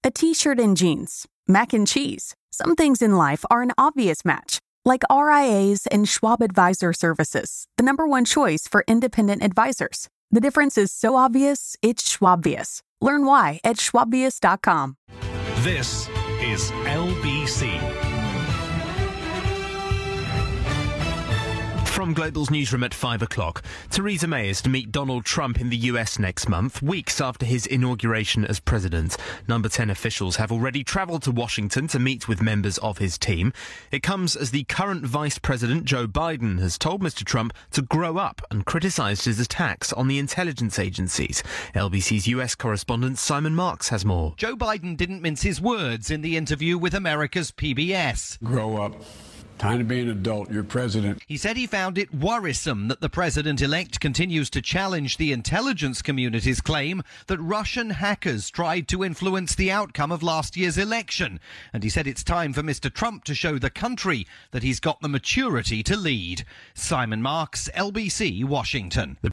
Here, via the UK's LBC.